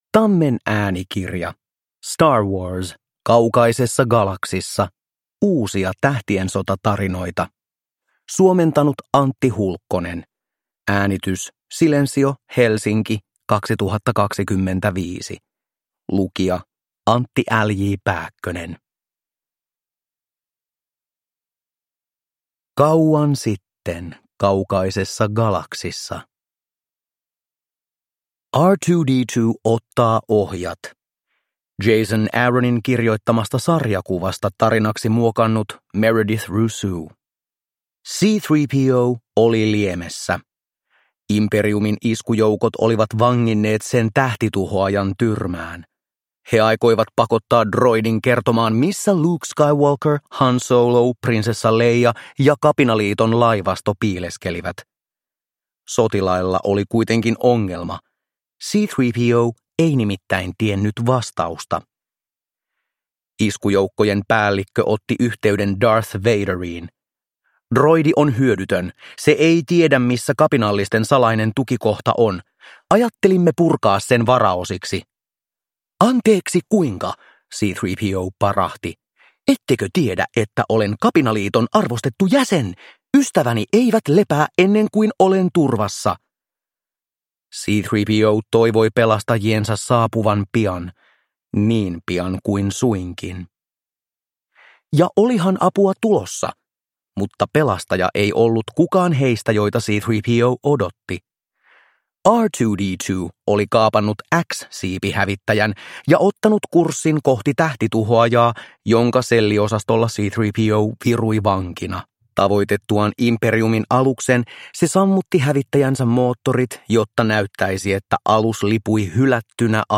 Star Wars. Kaukaisessa galaksissa (ljudbok) av Star Wars